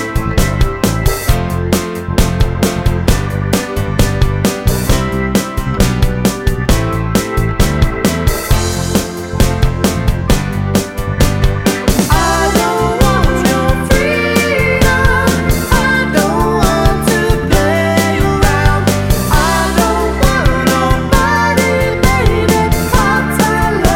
No Guitars Pop (1980s) 4:52 Buy £1.50